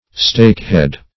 Stakehead \Stake"head`\ (st[=a]k"h[e^]d`)